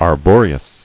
[ar·bo·re·ous]